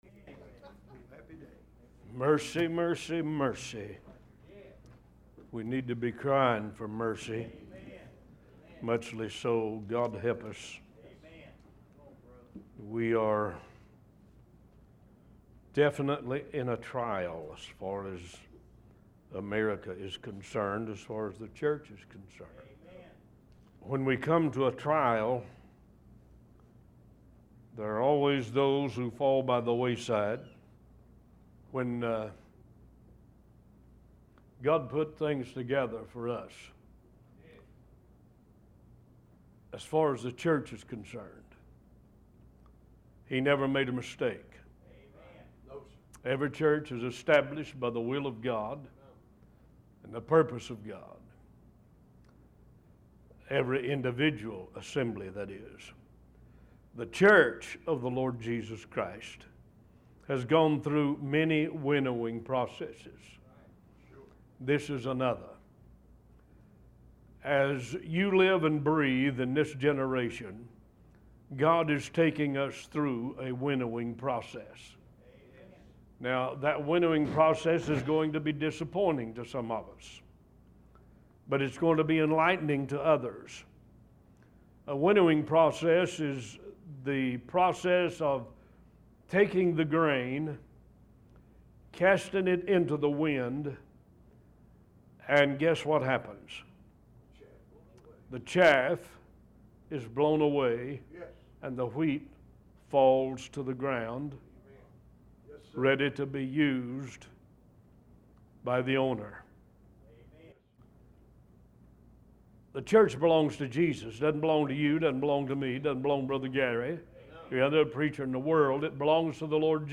New Sermons published every Sunday and Wednesday at 11:30 AM EST